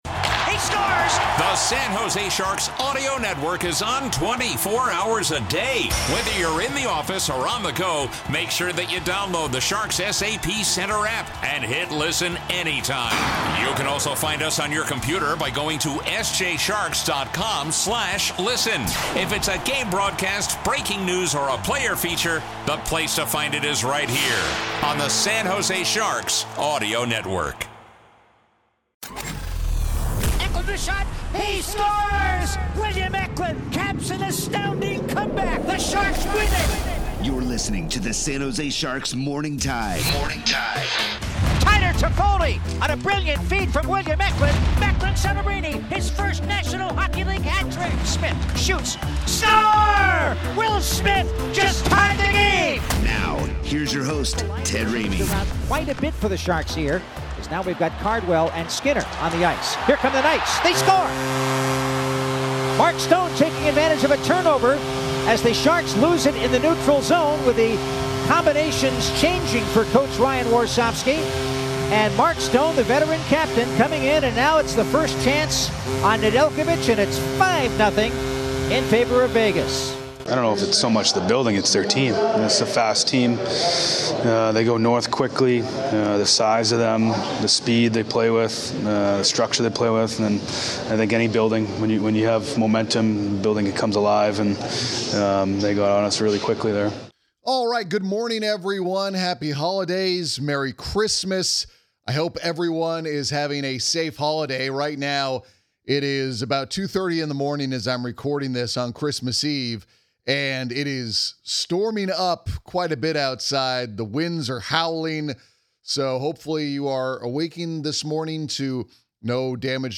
A podcast that will feature San Jose Sharks analysis, exclusive interviews and more